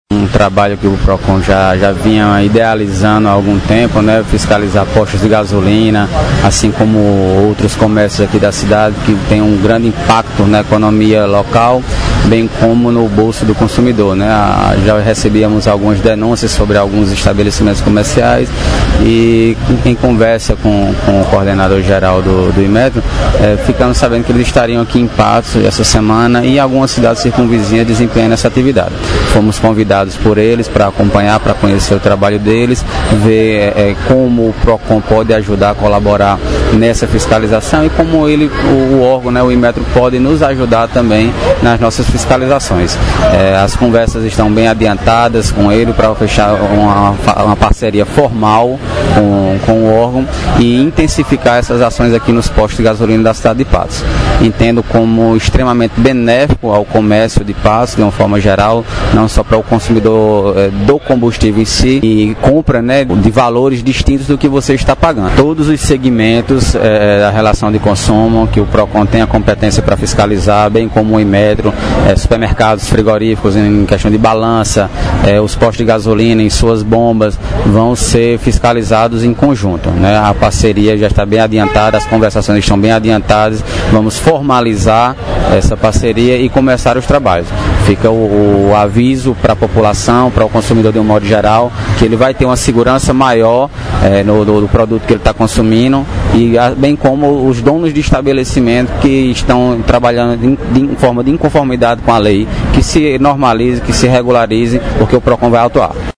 Fala do secretário do PROCON/Patos, Bruno Maia –